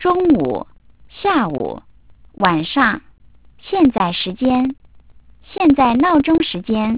- sampling rate : 8 kHz
reconstructed speech